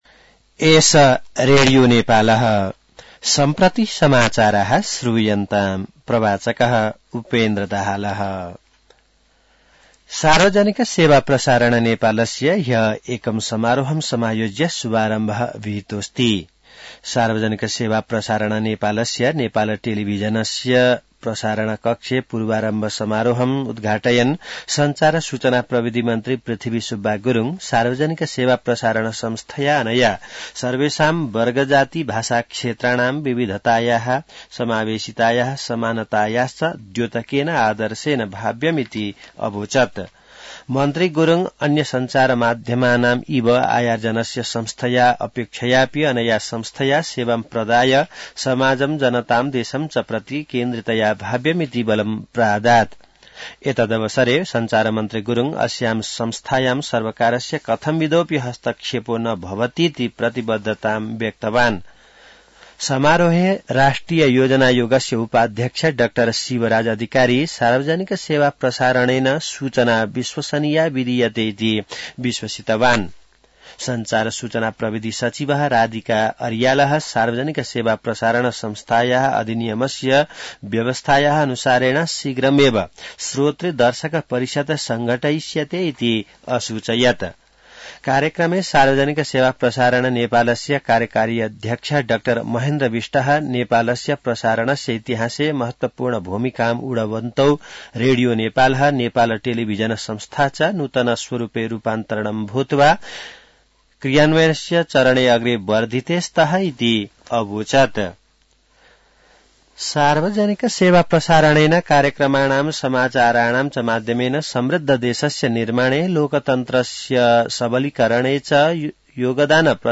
An online outlet of Nepal's national radio broadcaster
संस्कृत समाचार : ३ माघ , २०८१